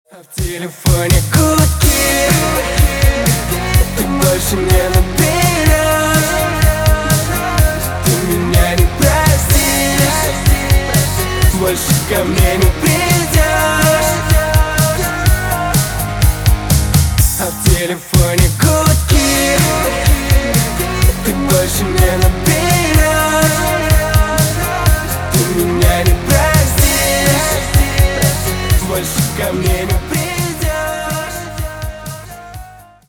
на русском грустные на бывшего